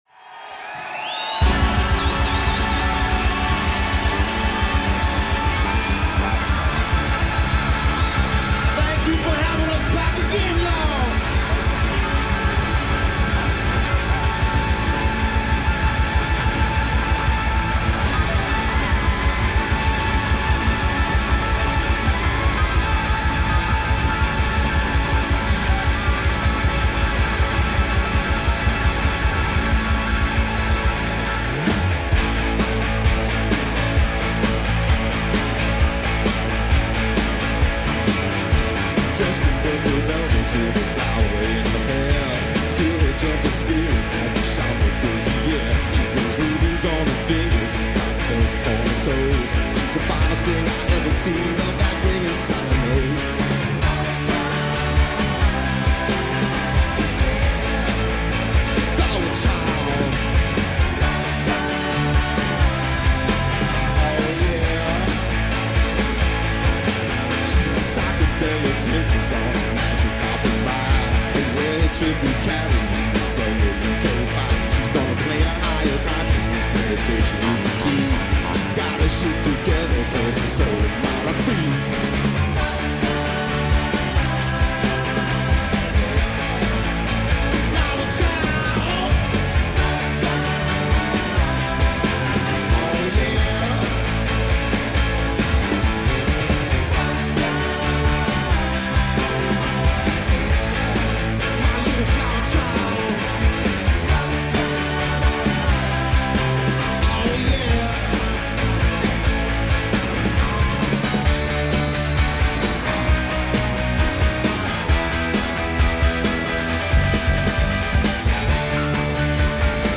live at The Town & Country